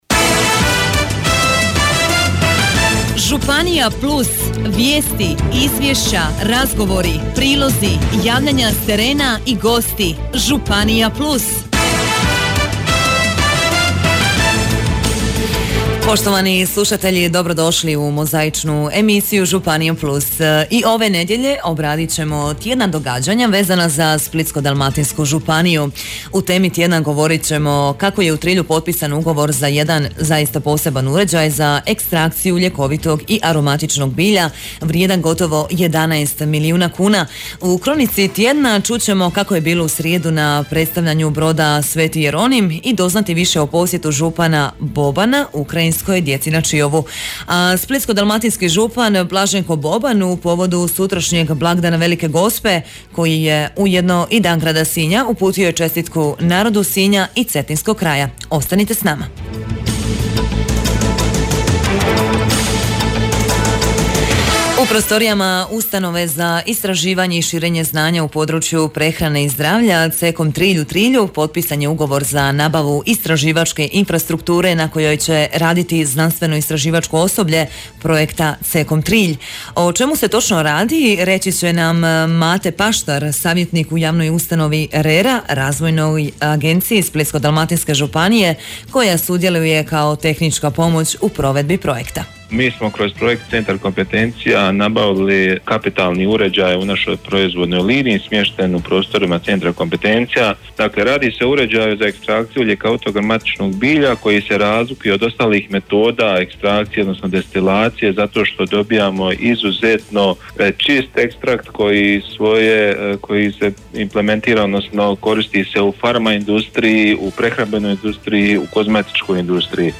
Danas, 14. kolovoza u programu Hit radija emitirana je nova emisija ‘Županija +’.